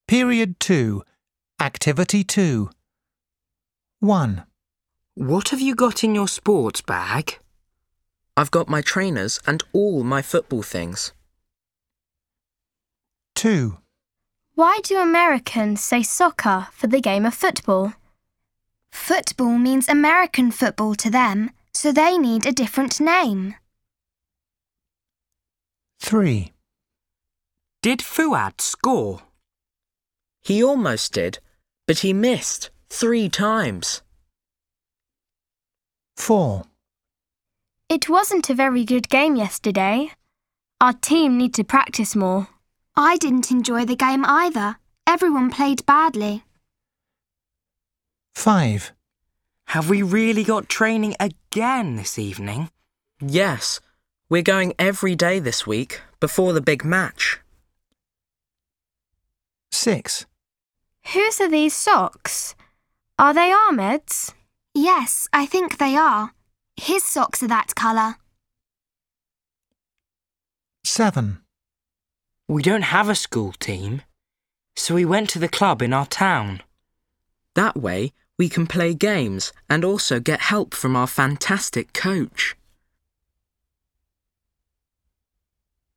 دروس الاستماع